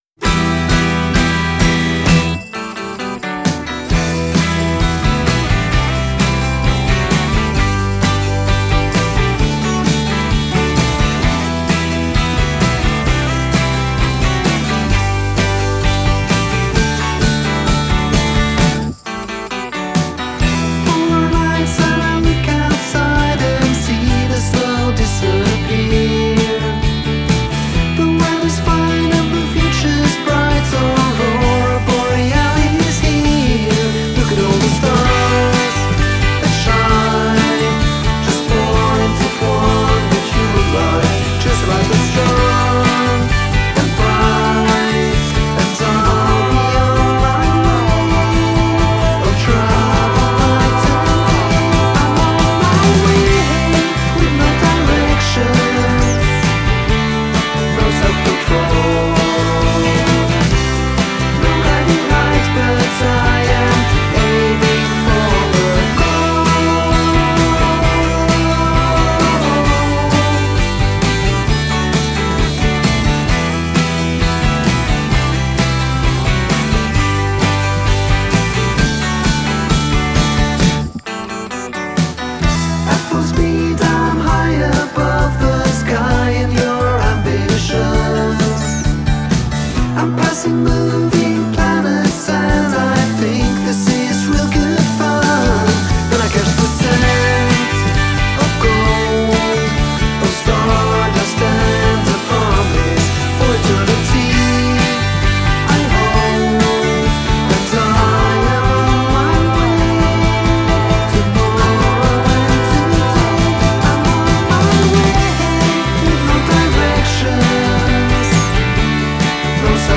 has a cool late 1960s indie vibe
and super chimey guitar work.